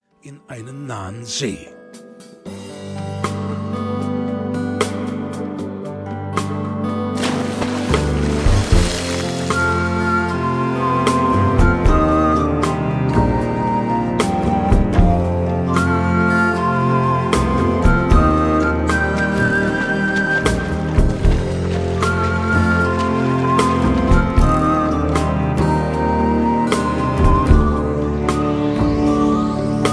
Aus dem Kindertanztheater